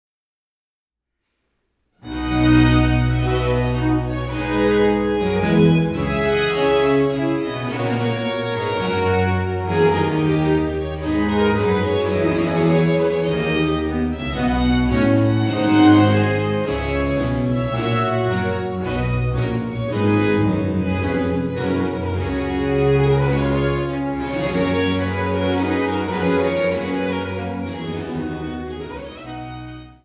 Instrumetalmusik für Hof, Kirche, Oper und Kammer